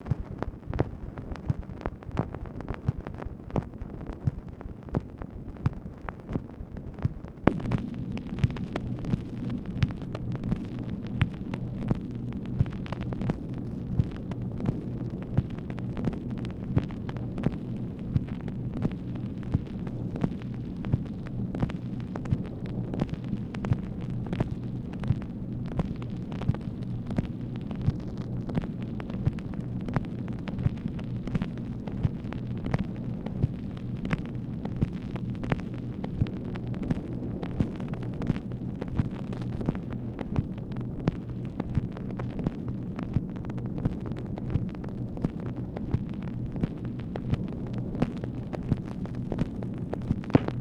MACHINE NOISE, April 30, 1965
Secret White House Tapes | Lyndon B. Johnson Presidency